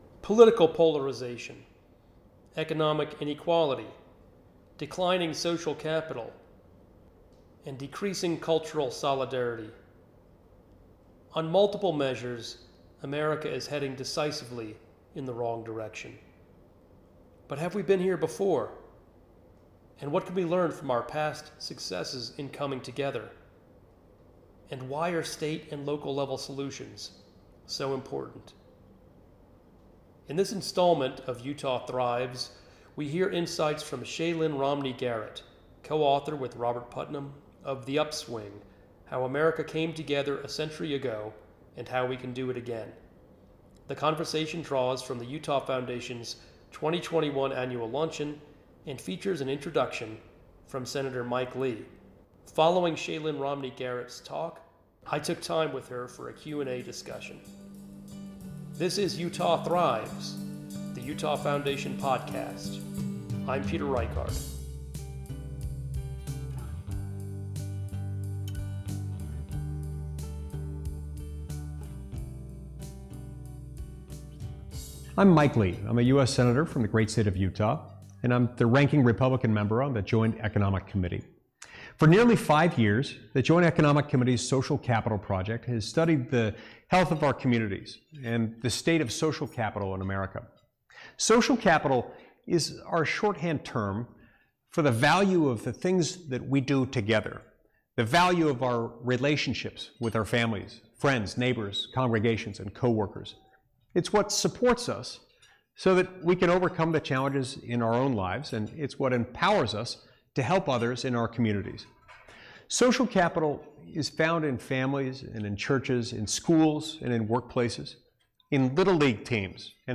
The conversation draws from the Utah Foundation’s 2021 Annual Luncheon and features an introduction from Sen. Mike Lee.